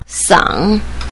sang3.mp3